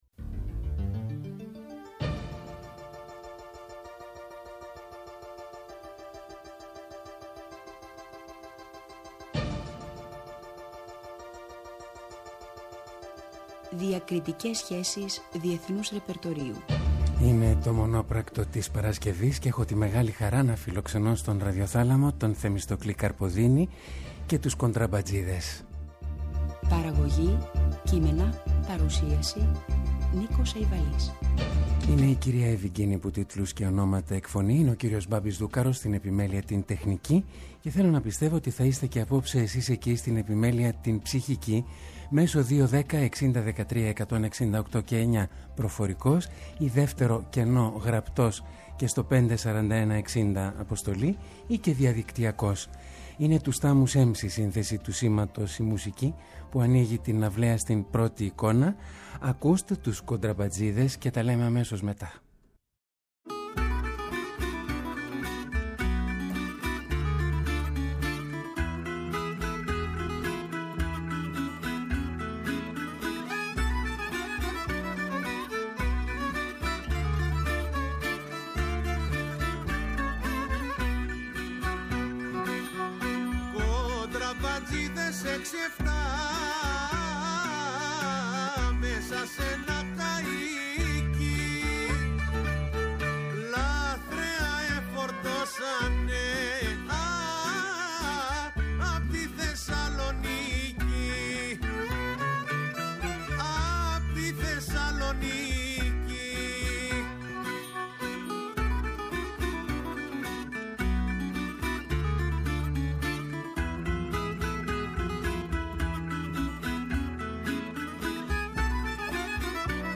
Αγαπημένα ρεμπέτικα τραγούδια σημαντικών Συνθετών μας, ερμηνευμένα λιτά και με πάθος από έξοχους Μουσικούς και την ξεχωριστή φωνή του.
ΔΕΥΤΕΡΟ ΠΡΟΓΡΑΜΜΑ Μουσική Συνεντεύξεις